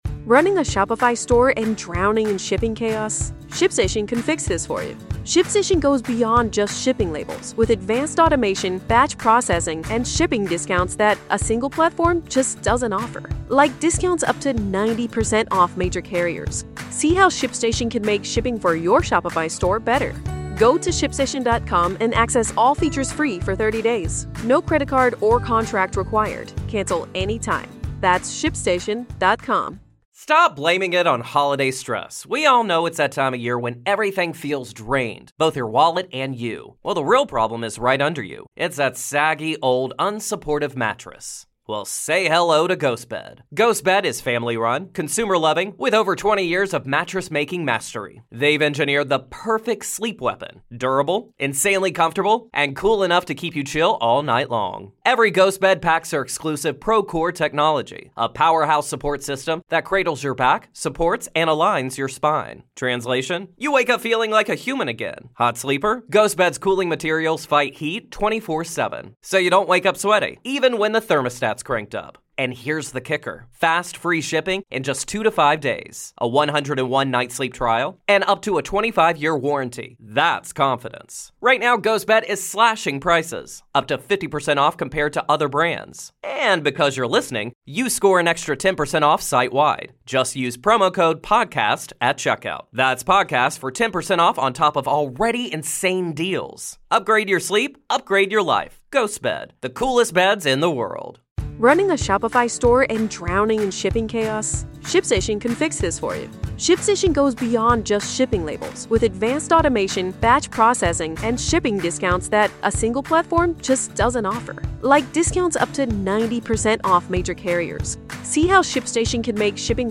We explore the implications of this new information for the prosecution’s case, the defense’s challenges, and what it could all mean for the trial set to begin in August 2025. Whether you're following the case closely or just tuning in, this conversation is your one-stop-shop for analysis you won’t find anywhere else.